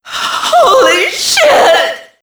Index of /server/sound/foot/quake/female